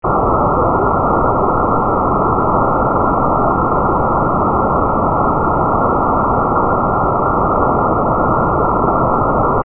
Demo 2: Pitch Identification
The signal ITD is fixed to lead one ear by 0.6 ms, so it will be perceived to be to one side of the central background noise. However, each 3-note sequence is either rising (400 Hz, 500 Hz, 600 Hz) or falling (600 Hz, 500 Hz, 400 Hz).